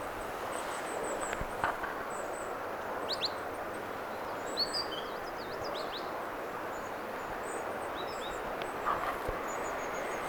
erikoinen talitiaisen ääntely
erikoinen_talitiaisen_aantely_voisi_olla_vaikkapa_kuvien_talitiainenkin_ehka.mp3